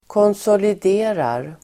Uttal: [kånsålid'e:rar]